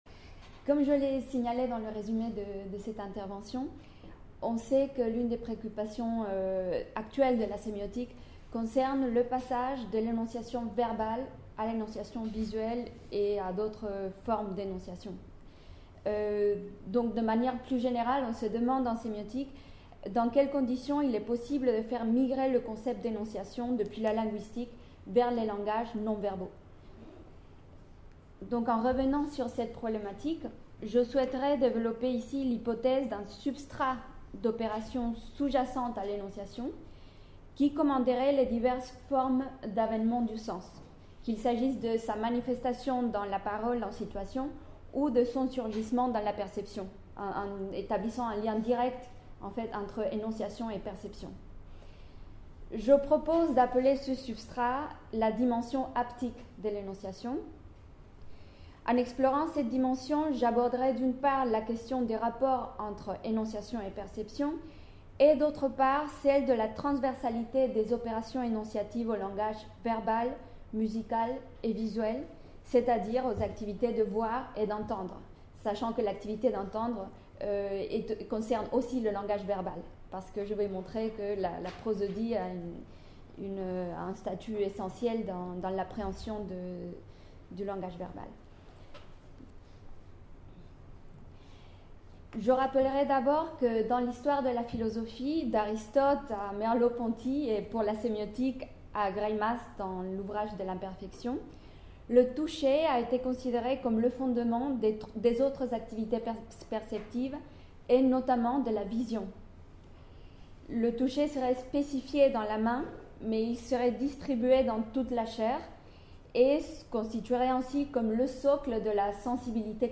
Séminaire | Musimédiane